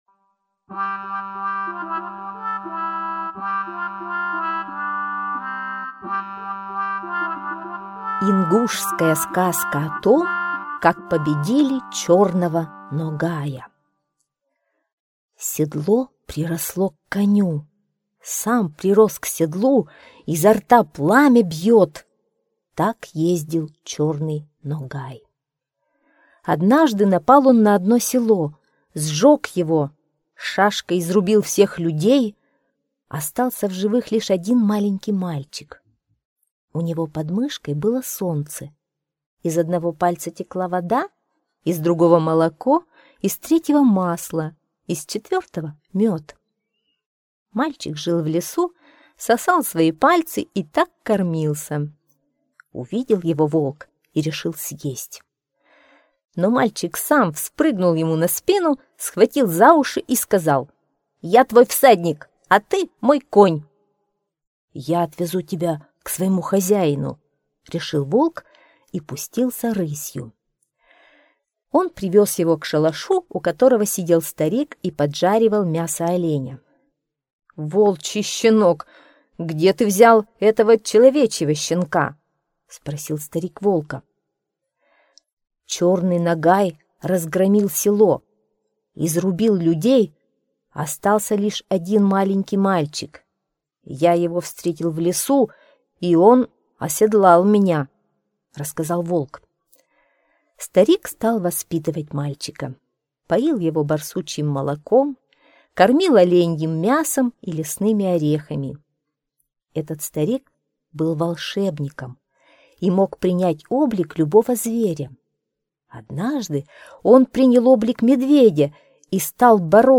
Сказка о том, как победили чёрного Ногая - ингушская аудиосказка.